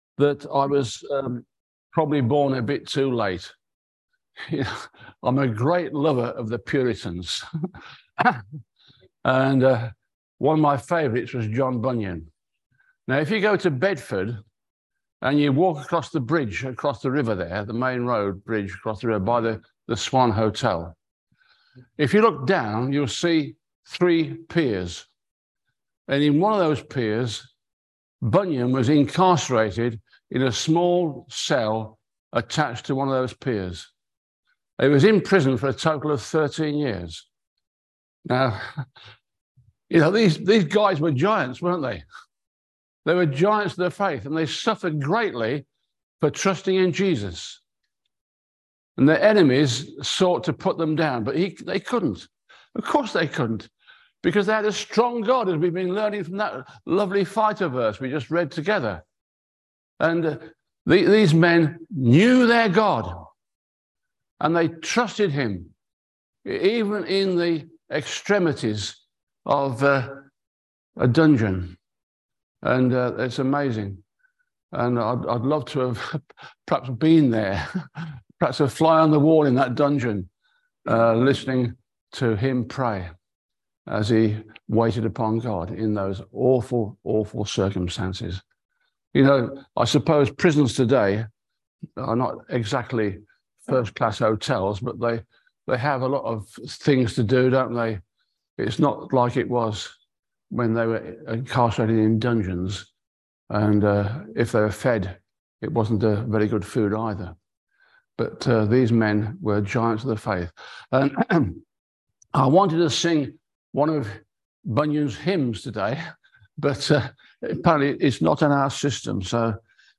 Passage: Philippians 1:27-29, Ephesians 4:1-6, 2 Thessalonians 1:11&12, 1 Peter 4:10&11, Colossians 1:9-14 Service Type: Sunday Service